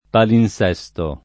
palinS$Sto] s. m. — nel sign. proprio («codice antico raschiato e riscritto»), rara oggi (più com. nell’800) la forma grecizzante palimpsesto [palimpS$Sto] — solo palinsesto nei sign. fig. («programmazione radiotelevisiva» e sim.) e negli usi scherzosi